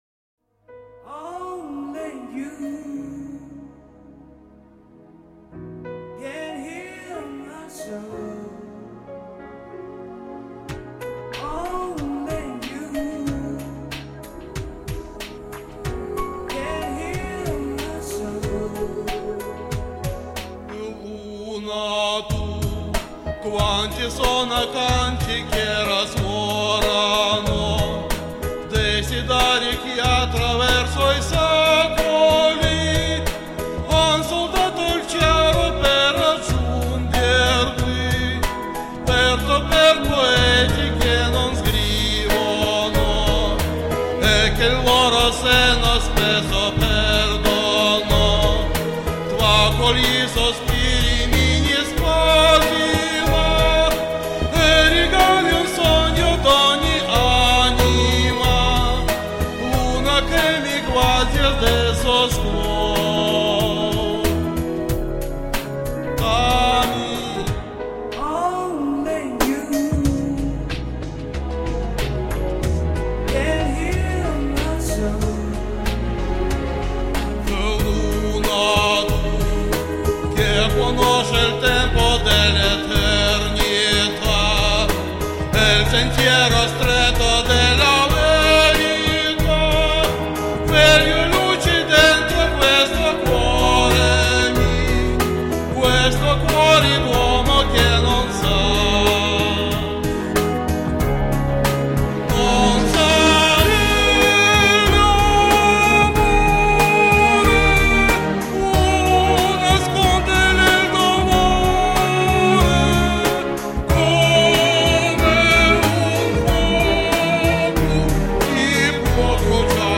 Трудно сравнивать женское и мужское исполнение